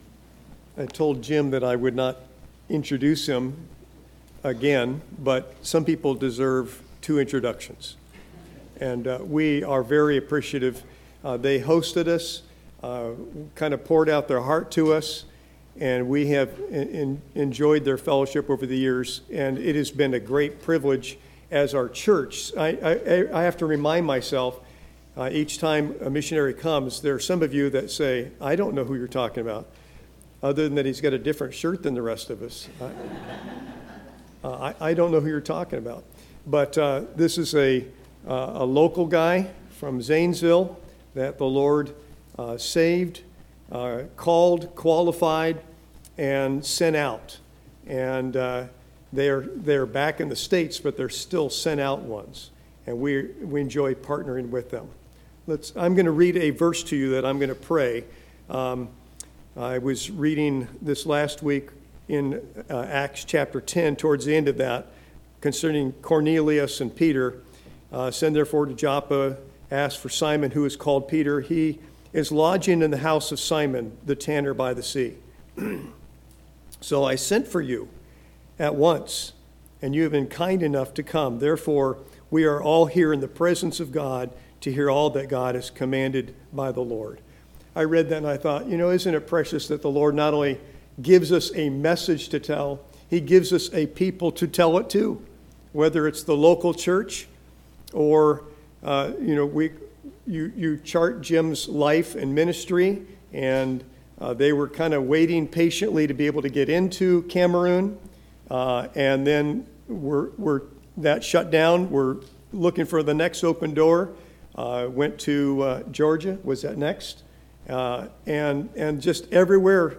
ServiceMissionary ConferenceSunday Morning